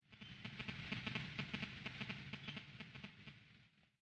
移动电话信号
描述：手机信号是手机数据信号的样本。